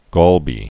(gälbē)